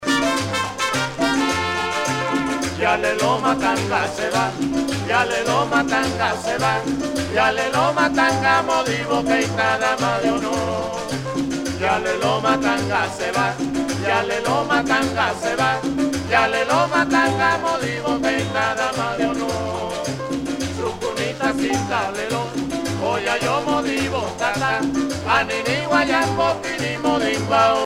danse : pachanga (Cuba)
Pièce musicale éditée